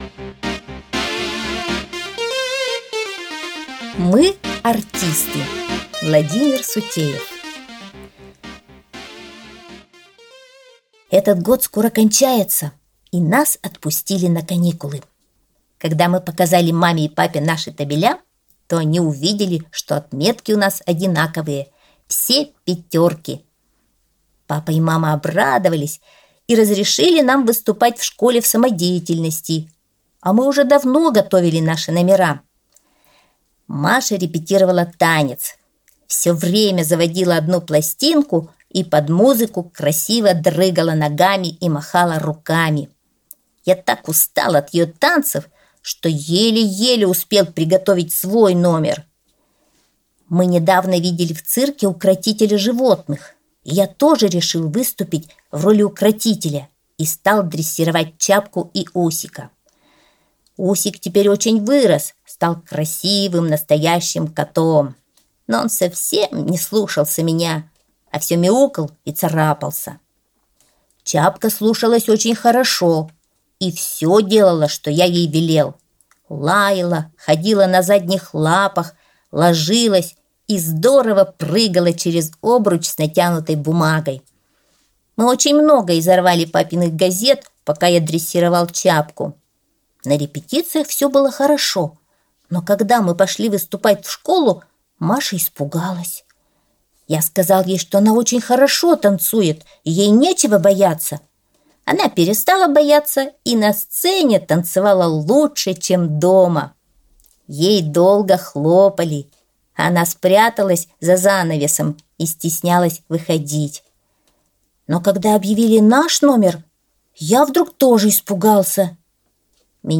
Аудиосказка «Мы-артисты»